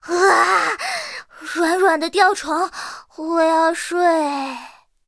M4A3E2小飞象中破修理语音.OGG